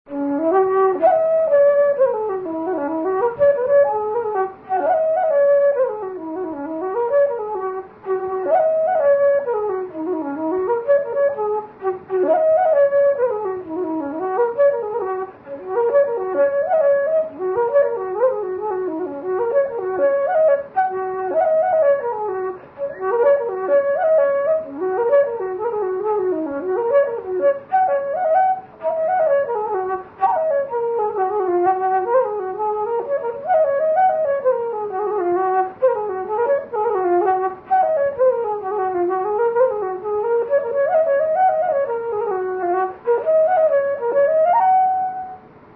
Here are a few short folk melodies I have composed.
Jigs and Reels